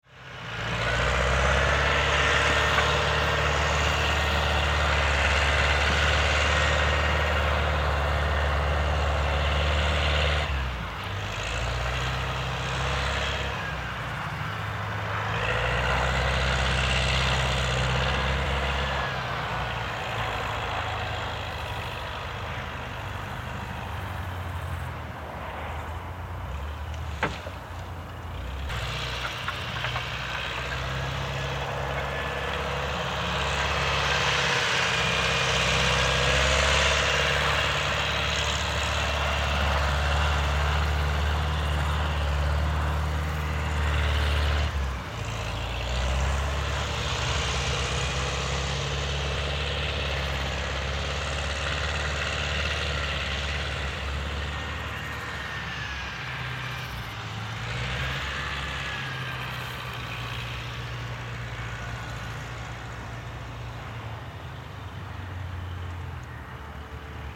Звуки бульдозера